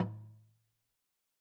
Percussion
LogDrumLo_MedM_v2_rr1_Sum.wav